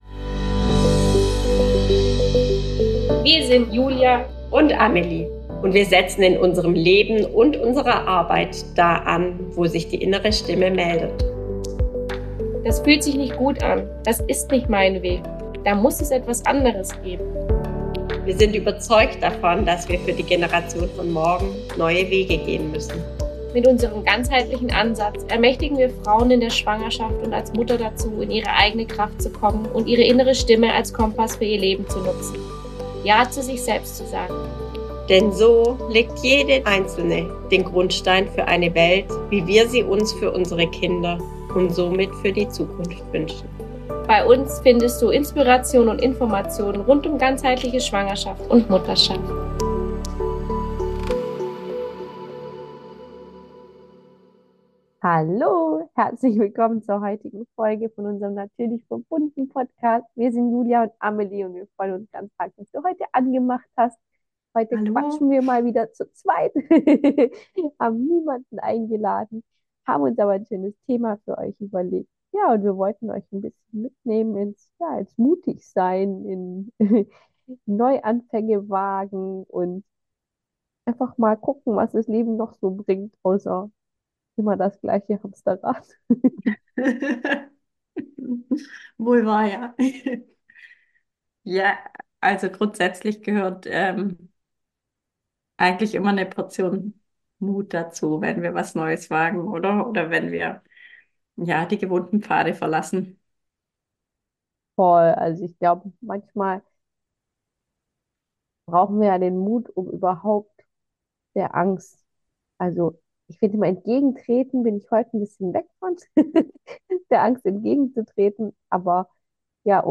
Hier kommt eine leicht chaotische aber super authentische Folge von uns beiden über Veränderungen und den Mut den es dazu braucht. Es geht auch um Mut zu einem authentischen Leben jenseits der "Norm".